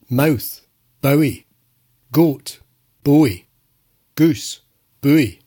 Scottish MOUTH and GOAT
bowie_scottish.mp3